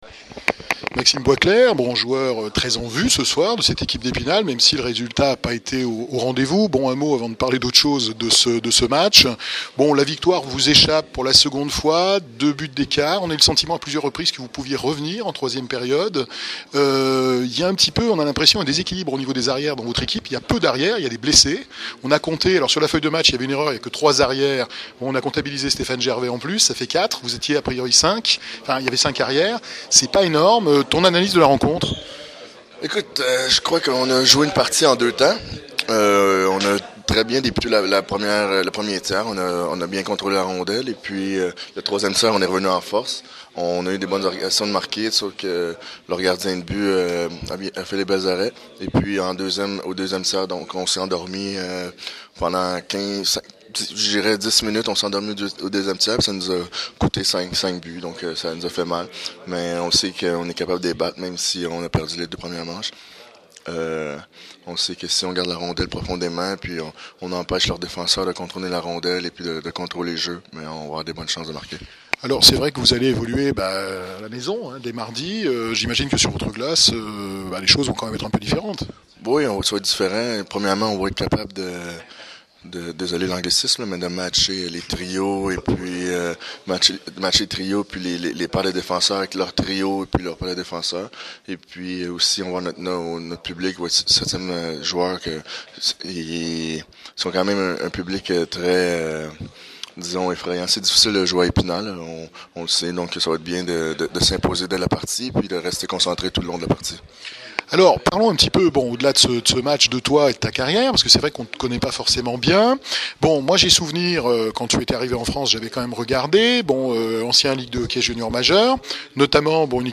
Hockey sur glace : Interview Epinal